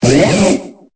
Cri de Limaspeed dans Pokémon Épée et Bouclier.